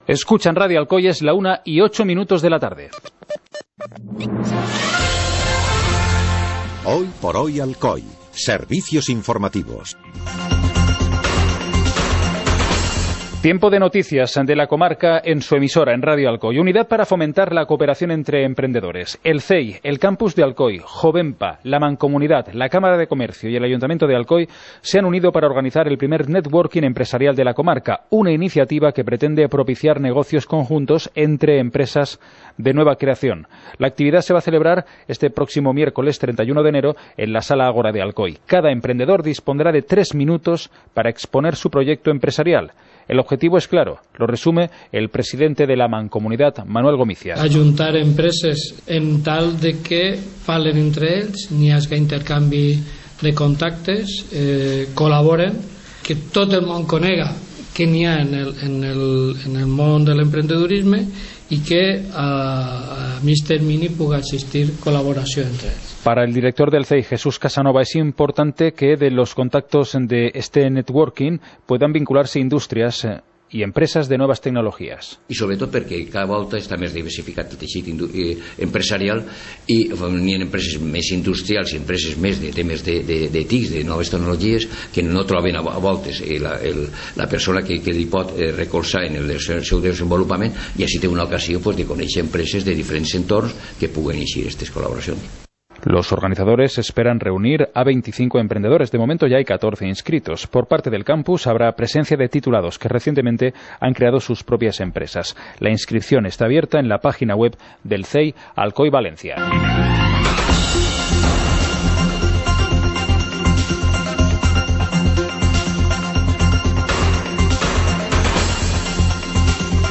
Informativo comarcal - jueves, 25 de enero de 2018